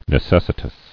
[ne·ces·si·tous]